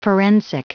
Prononciation du mot forensic en anglais (fichier audio)
Prononciation du mot : forensic